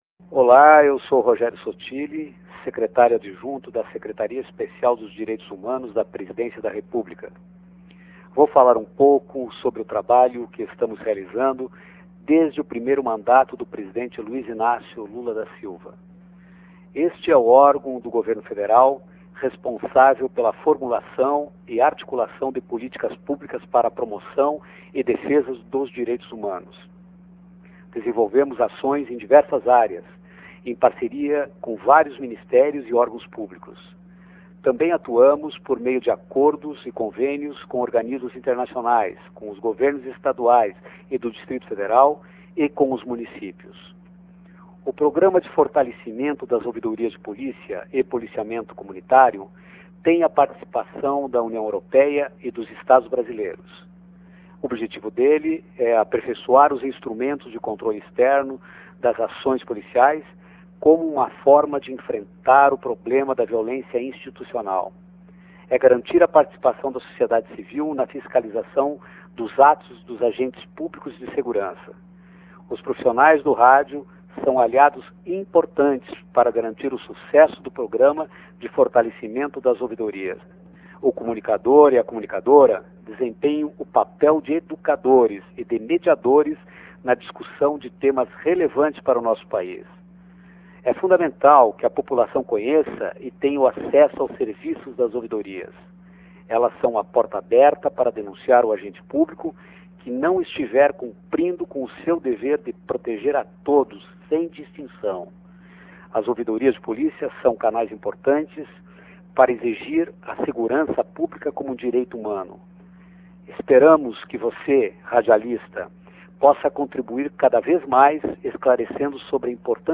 Faixa 1 - Carta Falada Rogério Sottili Faixa 8 - MPB 3